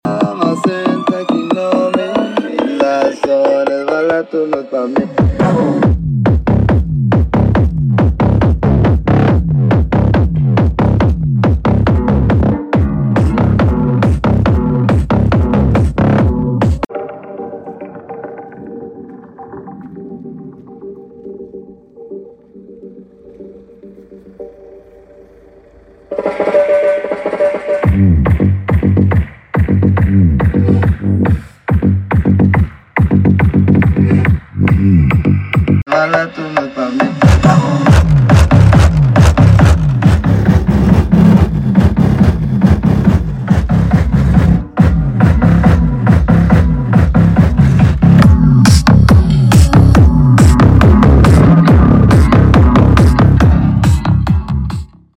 helt störd midbas o snärt